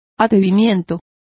Complete with pronunciation of the translation of impudence.